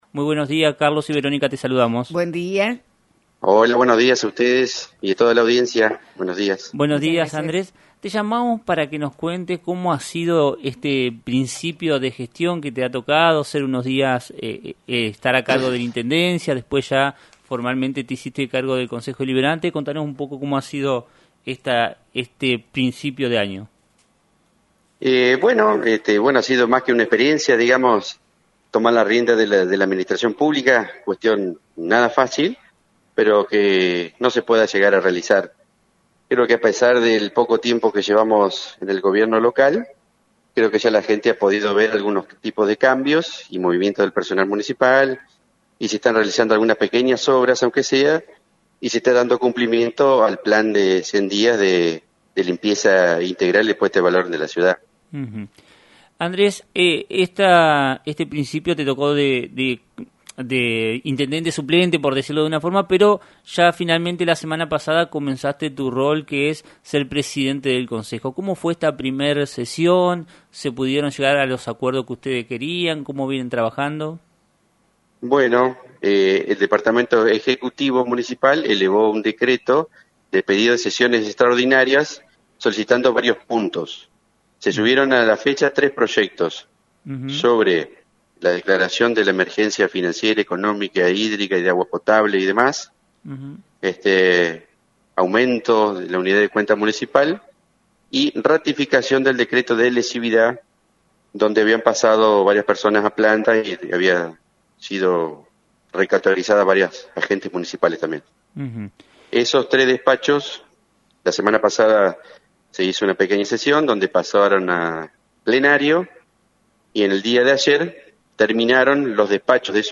En una entrevista con “Burro de arranque” por FM90.3, Andrés Marchese, quien ha estado a cargo de la Intendencia y actual presidente del Consejo Deliberante.